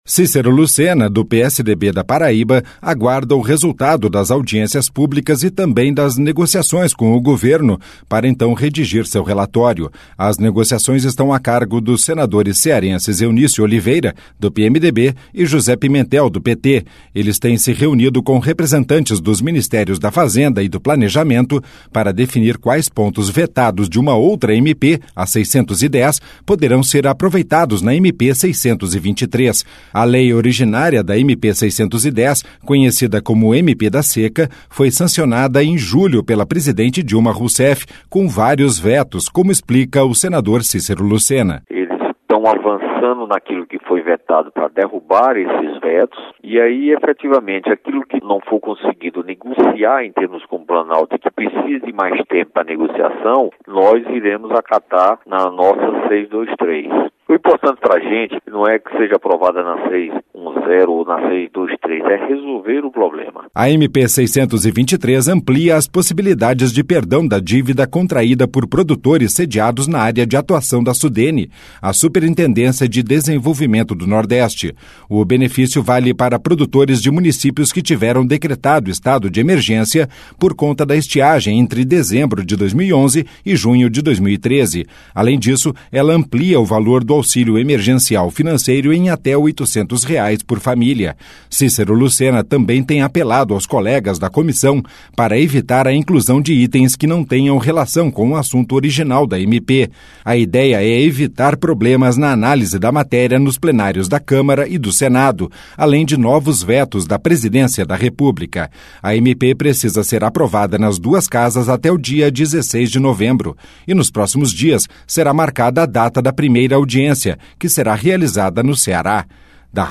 LOC: E O RELATOR DA MATÉRIA, SENADOR CÍCERO LUCENA, FALOU DAS NEGOCIAÇÕES PARA ACELERAR A VOTAÇÃO DA EMEPÊ.